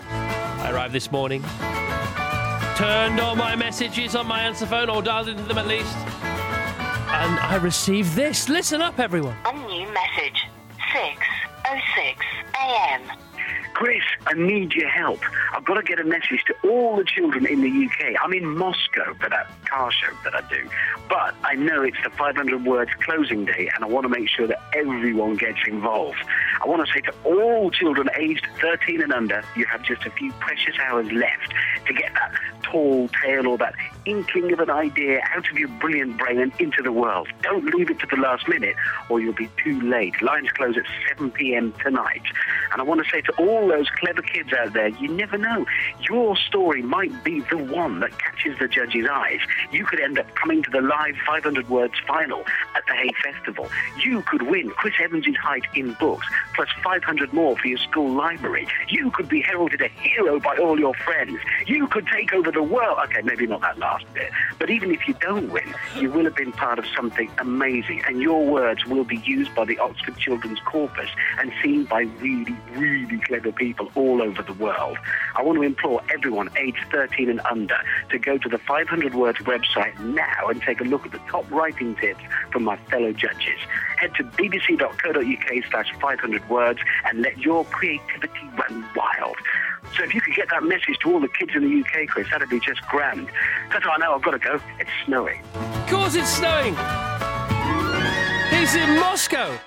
Richard Hammond leaves Chris Evans a voicemail...